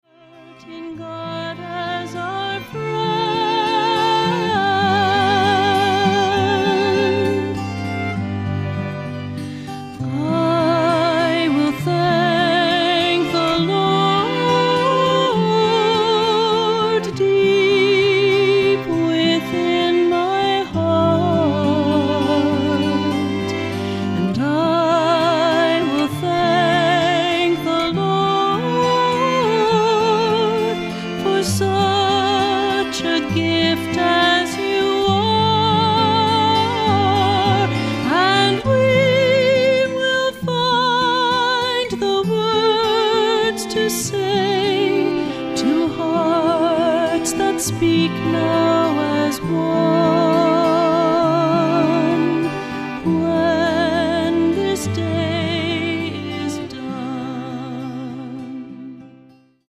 Voicing: SATB; Solo